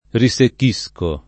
risecchire v.; risecchisco [
riSekk&Sko], -sci — meno com. riseccare: risecco [riS%kko], -chi; che accanto al sign. di «diventar secco» ha in più il valore trans. di «disseccare» — usati spesso come agg. e part. pass. risecchito [riSekk&to] e, per «disseccato», risecco [riS%kko] (pl. m. -chi), meno spesso riseccato [riSekk#to]